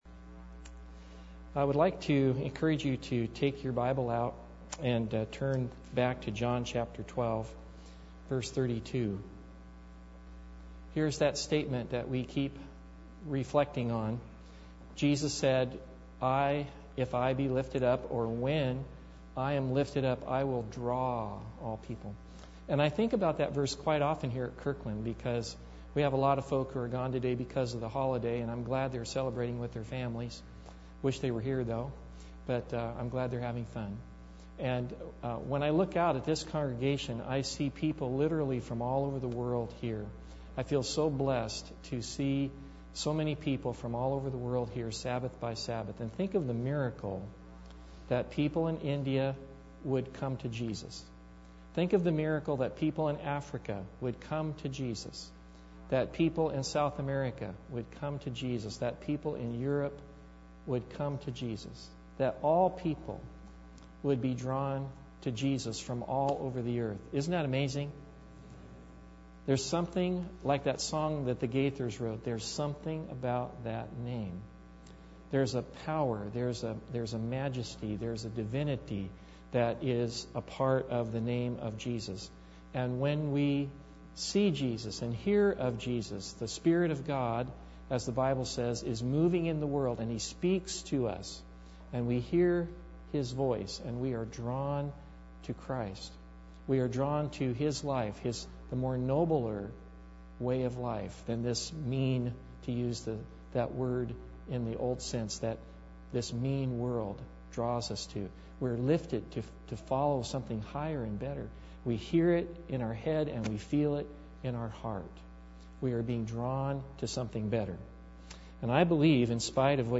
John 12:32 Service Type: Sabbath Bible Text